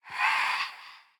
Minecraft Version Minecraft Version snapshot Latest Release | Latest Snapshot snapshot / assets / minecraft / sounds / mob / phantom / idle2.ogg Compare With Compare With Latest Release | Latest Snapshot